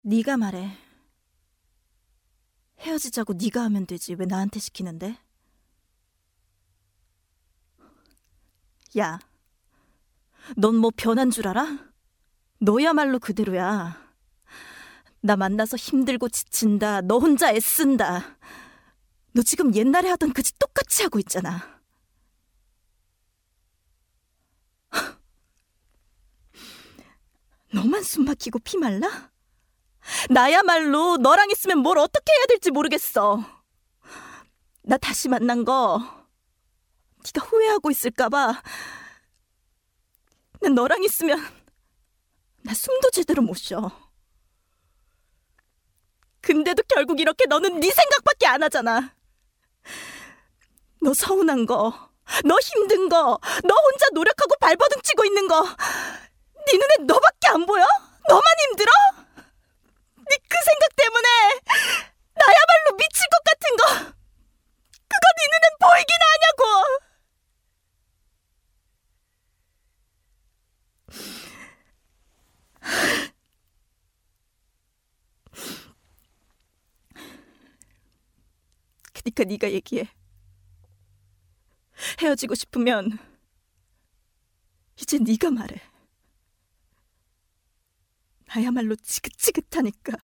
성우샘플